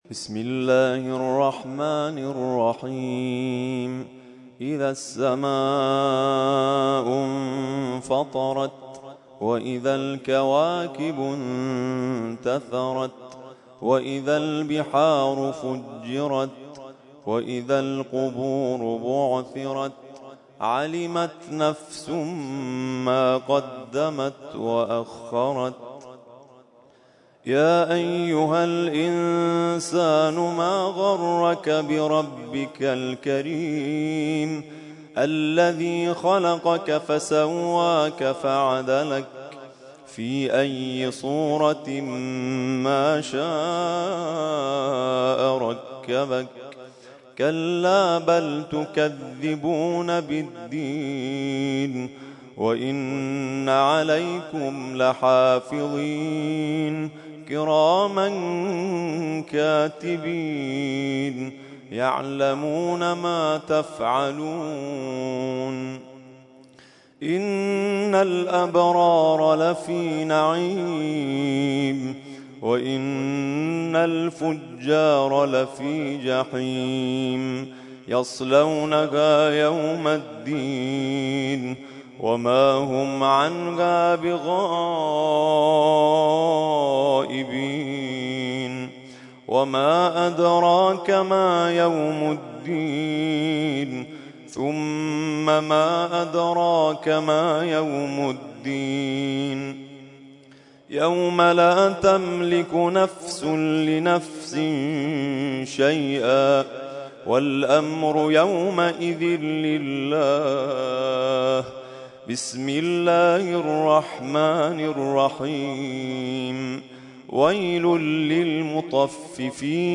ترتیل خوانی جزء ۳۰ قرآن کریم در سال ۱۳۹۴